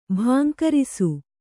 ♪ bhankarisu